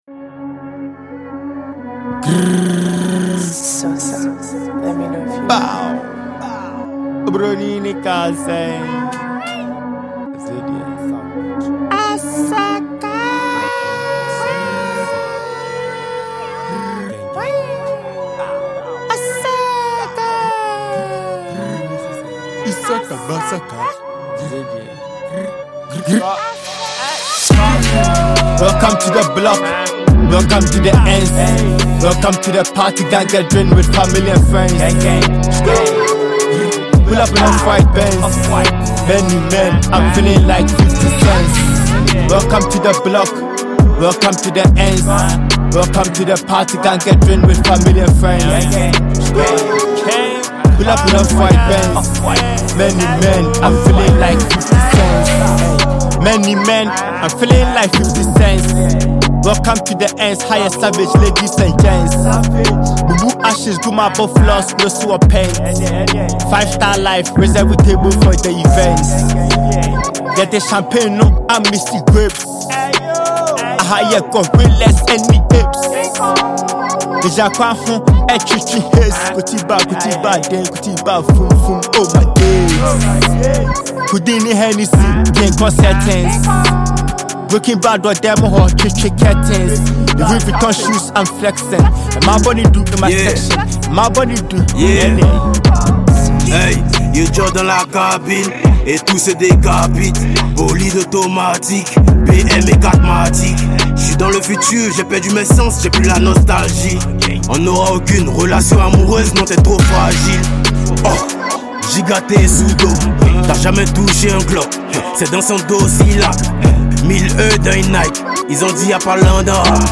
a prolific Ghanaian hip-hop, afrobeat artist